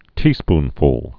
(tēspn-fl)